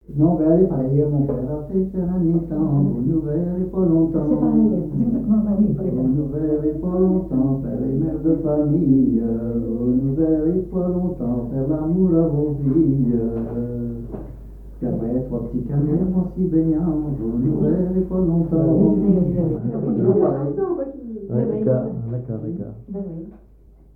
Fonction d'après l'analyste gestuel : à marcher
Genre laisse
Pièce musicale inédite